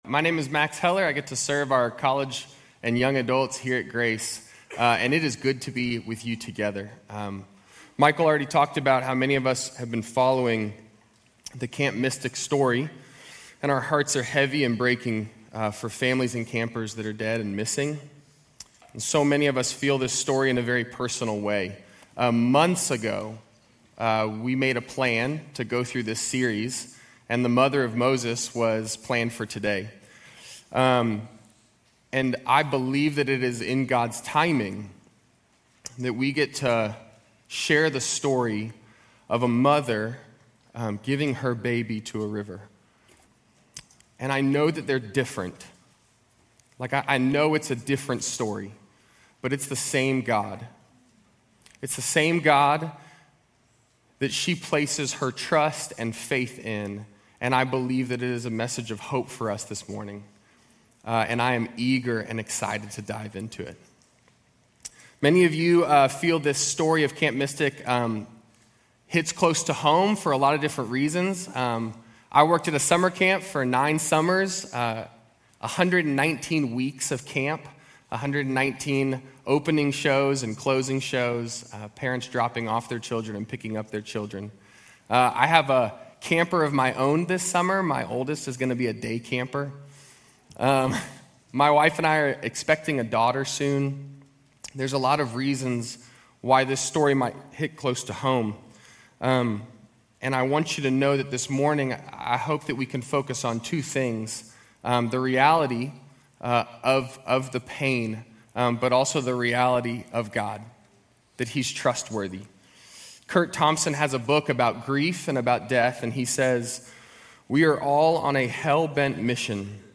Grace Community Church University Blvd Campus Sermons 7_6 University Blvd Campus Jul 06 2025 | 00:35:05 1x 00:00 / 00:35:05 Subscribe Share RSS Feed Share Link Embed